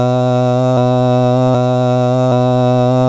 AKG C24 Stereo Vacuum Tube (valve) microphone evaluation.
Great River transformerless mic preamp to Sony PCM-R500 RDAT.
Two Times mono loop of the "AHH" test that quickly reveals the differences between the two mics.
brass-nylon_AH_LoopX2.wav